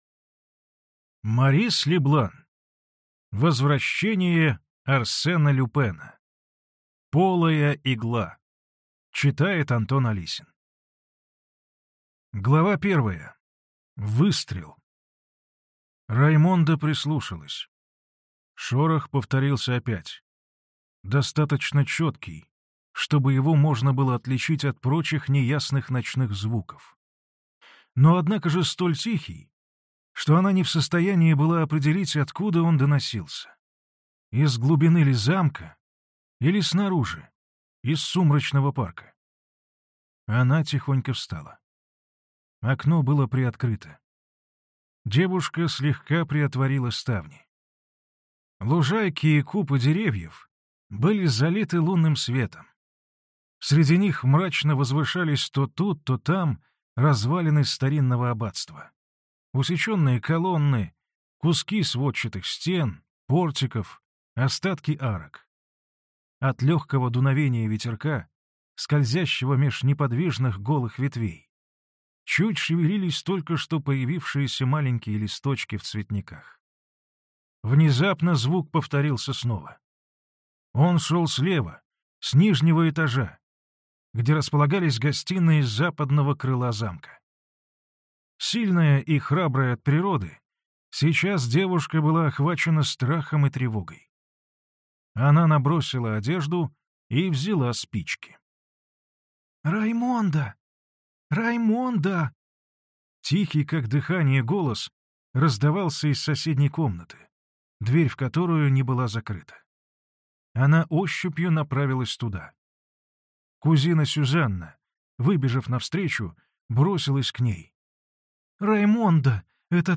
Аудиокнига Полая игла | Библиотека аудиокниг
Прослушать и бесплатно скачать фрагмент аудиокниги